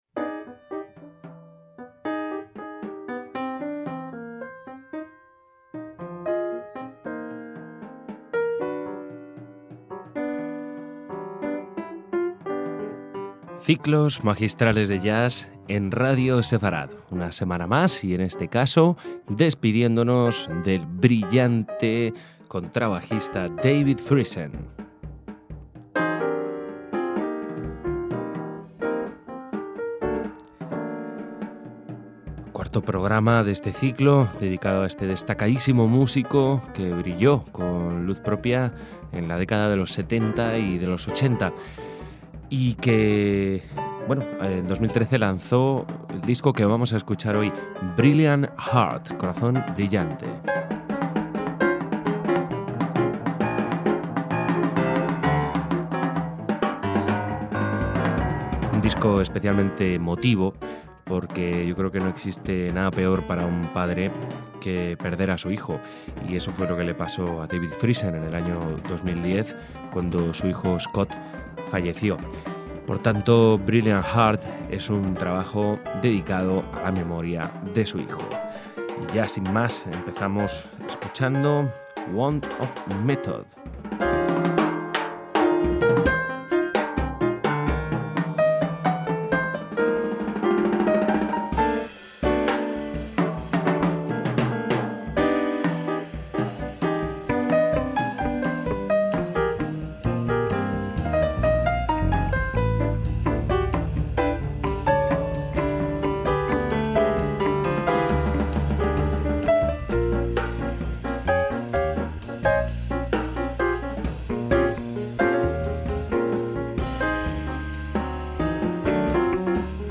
Como es de esperar es un trabajo muy lírico e íntimo.
pianista
guitarrista